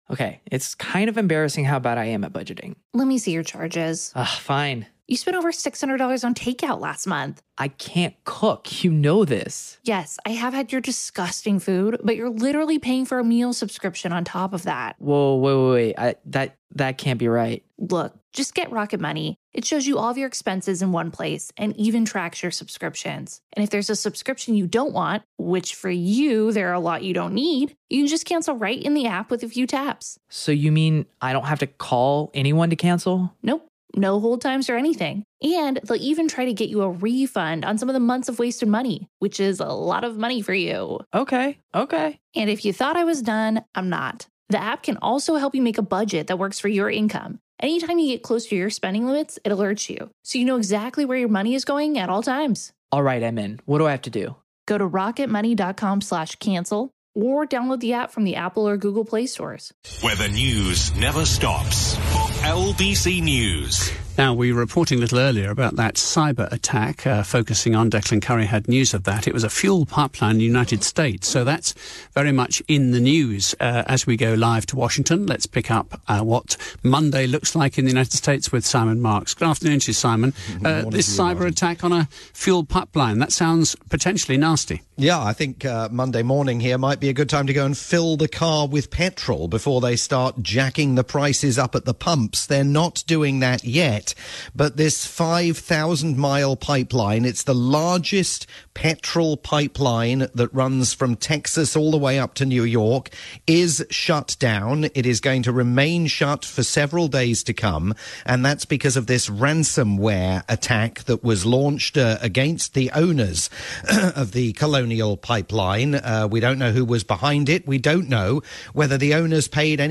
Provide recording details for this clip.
live roundup